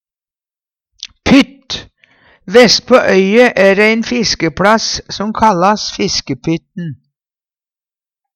pytt - Numedalsmål (en-US)